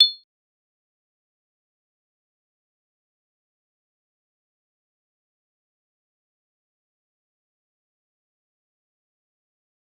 G_Kalimba-A8-mf.wav